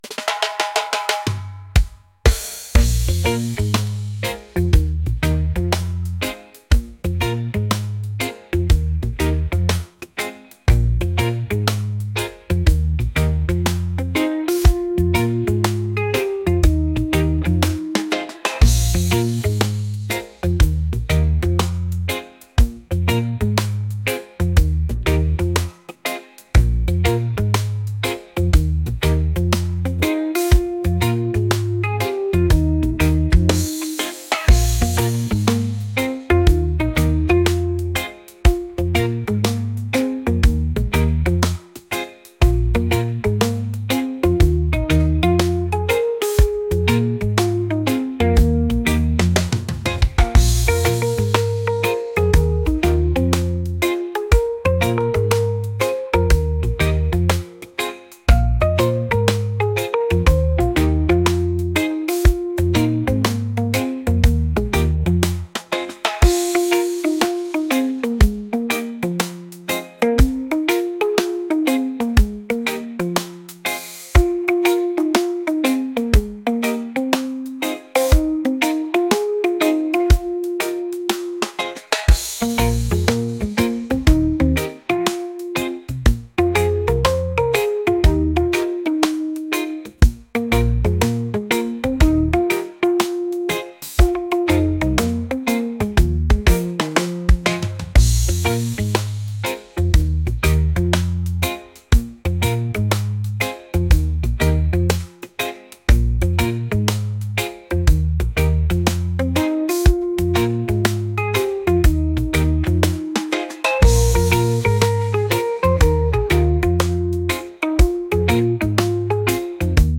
reggae | pop